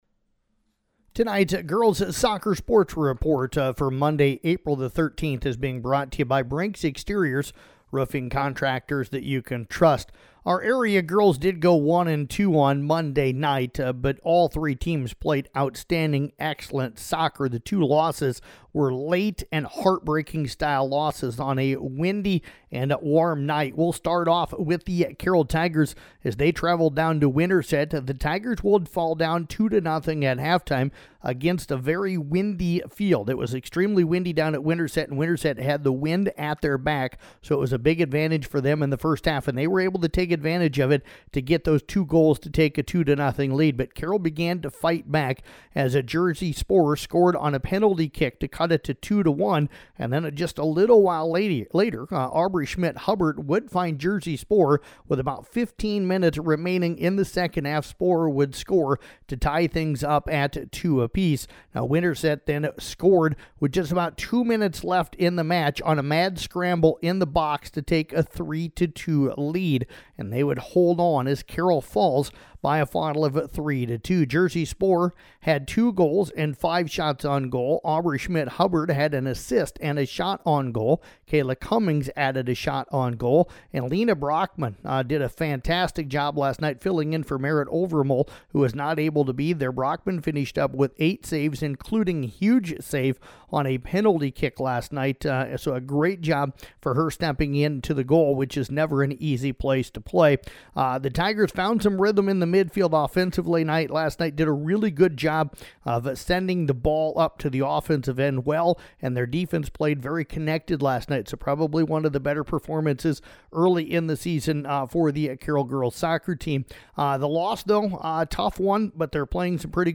Below is an audio recap for Girls Soccer for Monday, April 13th
girls-soccer-report-monday-april-10th.mp3